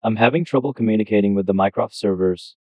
TTS cache again.